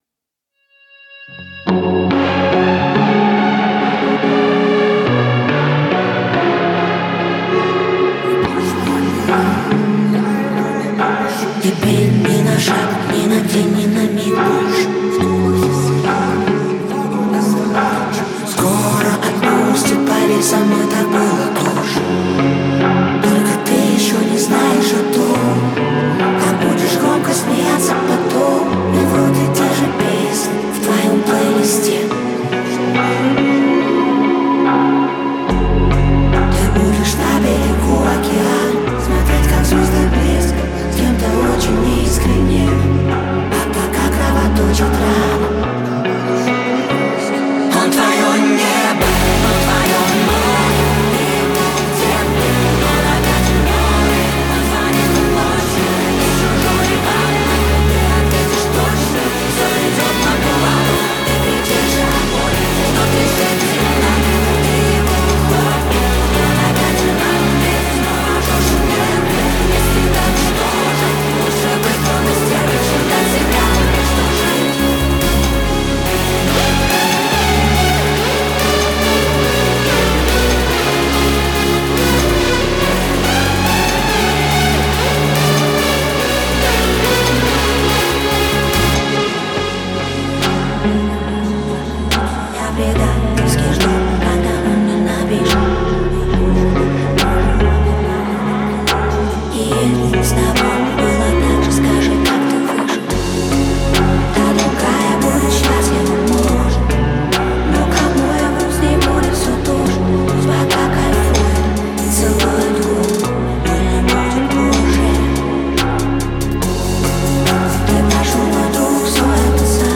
Для удобства - его рендер.